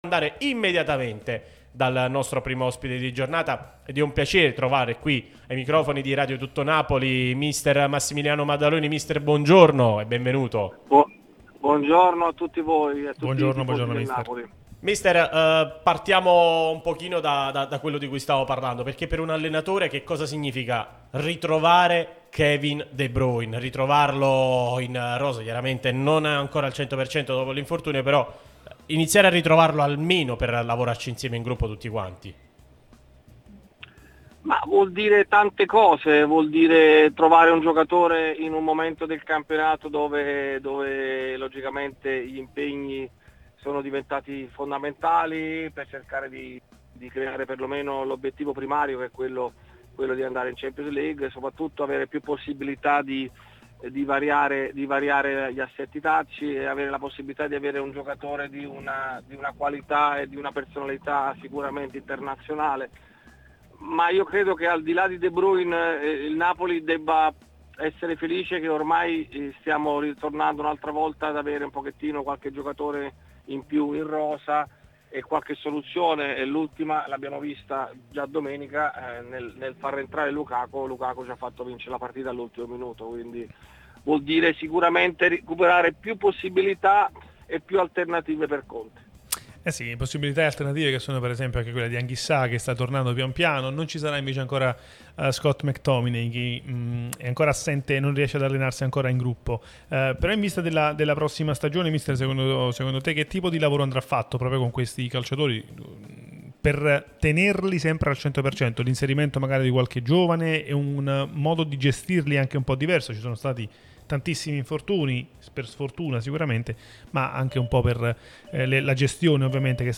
allenatore